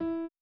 01_院长房间_钢琴_01.wav